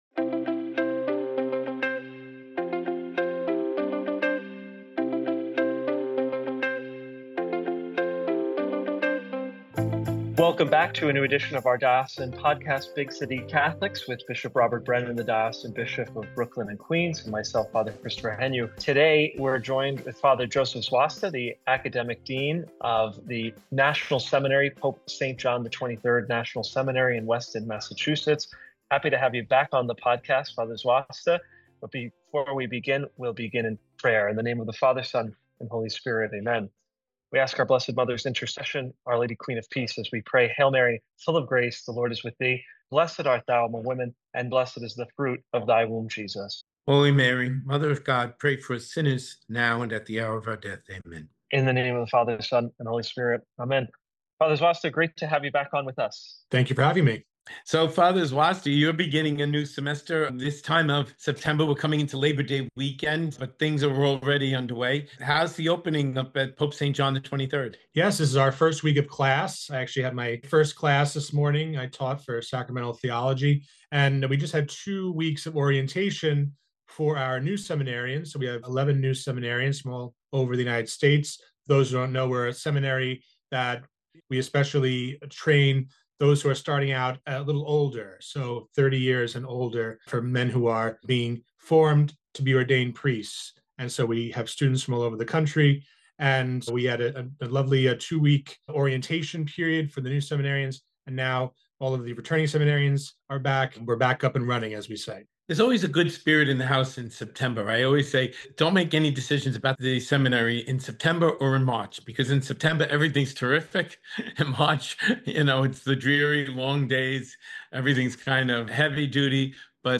In this intimate conversation